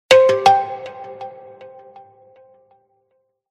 InnJoo_Notification_1.mp3